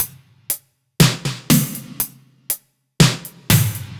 Index of /musicradar/french-house-chillout-samples/120bpm/Beats
FHC_BeatC_120-01_NoKick.wav